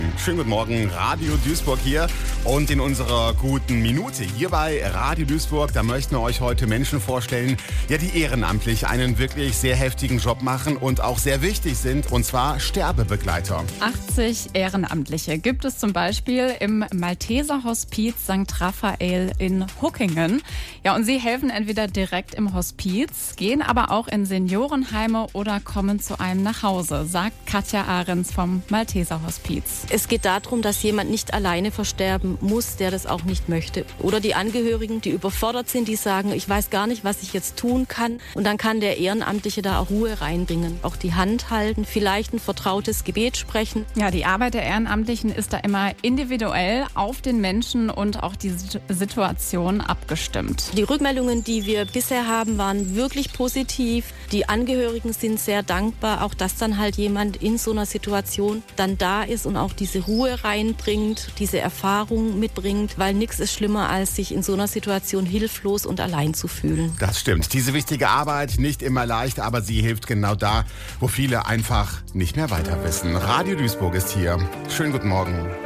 Malteser-Hospiz-Radio-Mitschnitt.mp3